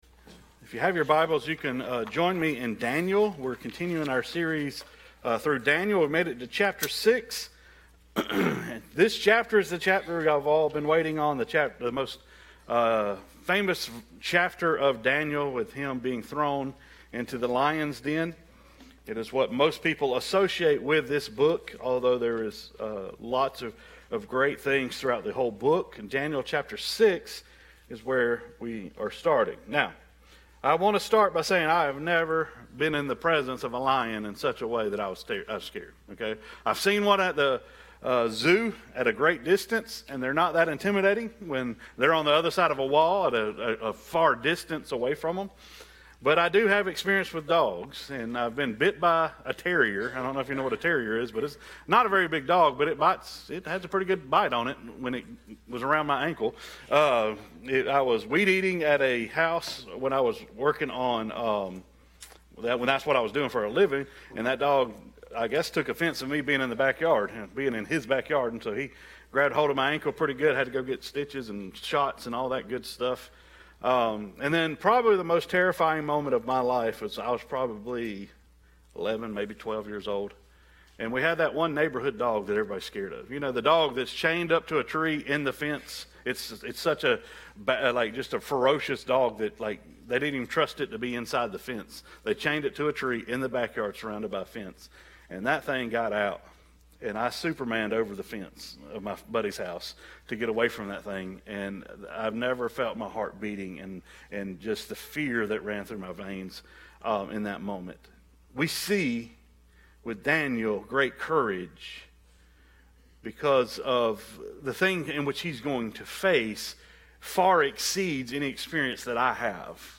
Sermons | Piney Grove Baptist Church